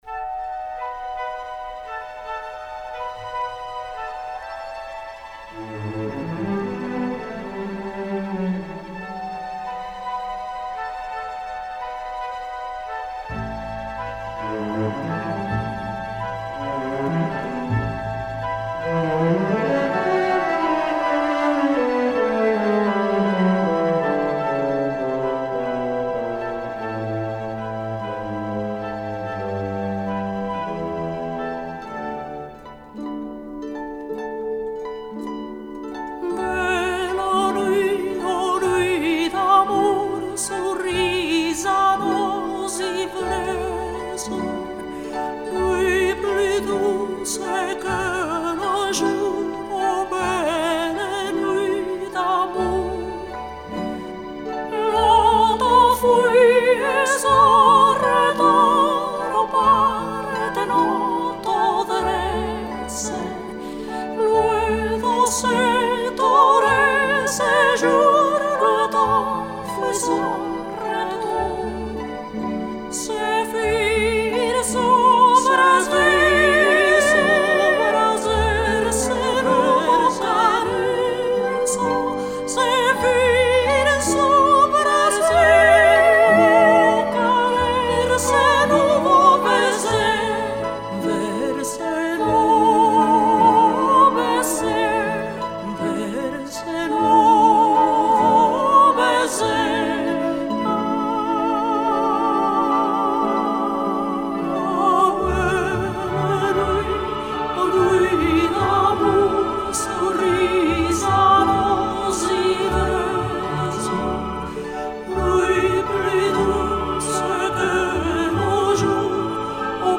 исполнителя популярных оперных арий